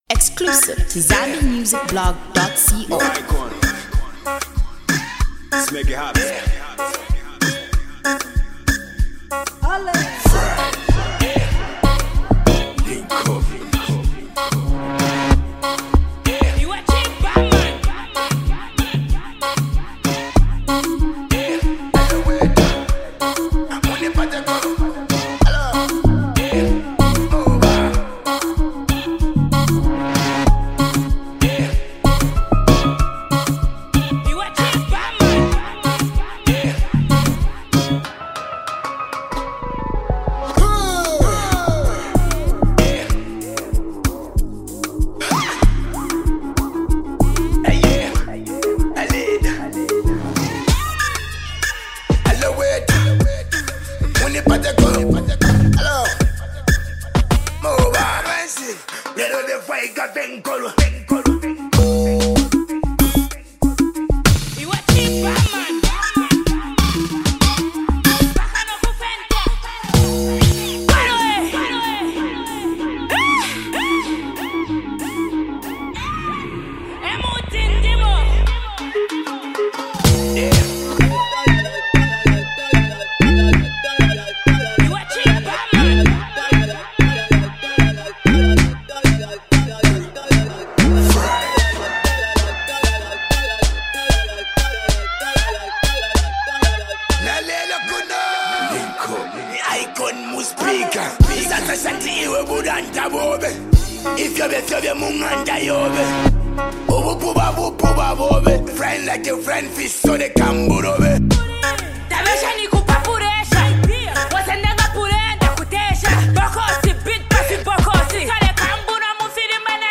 On this certified club banger
female rapper